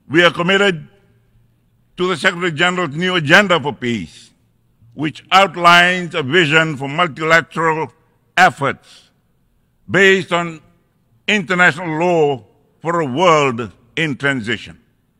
Prime Minister Sitiveni Rabuka highlighted this while delivering his address at the UN General Assembly in New York.